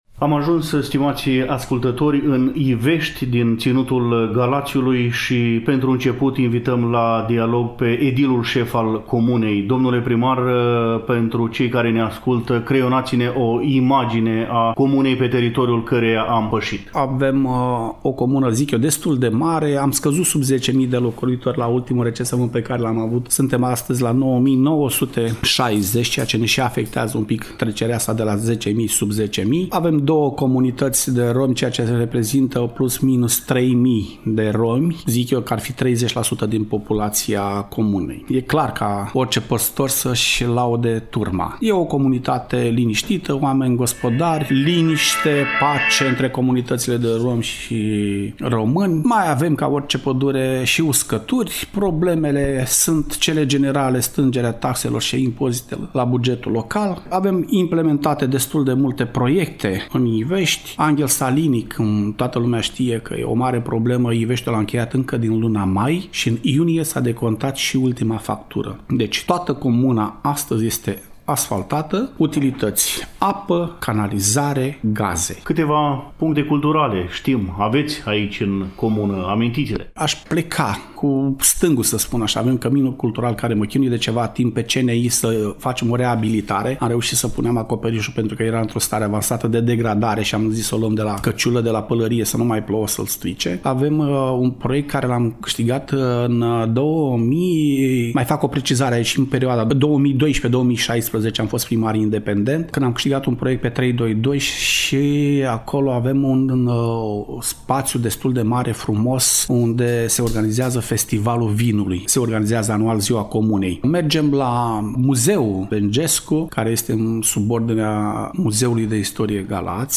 Stăm de vorbă, pentru început, cu Marcel Ghioca, edilul șef al comunei Ivești, cel care ne descrie comunitatea pe care o păstorește, cu accent pe proiectele implementate în ultima perioadă în comună. Viața romilor din Ivești, cu plusuri și minusuri, alt subiect al dialogului.